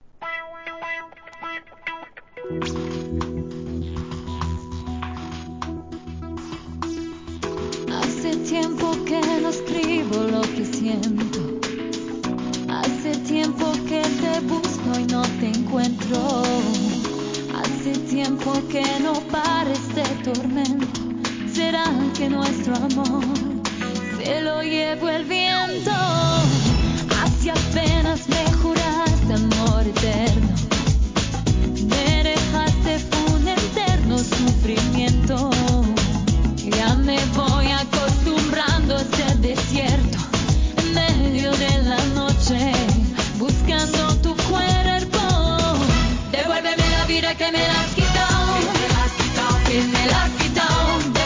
HIP HOP/R&B
POPな爽快ラテンHOUSE REMIXも!!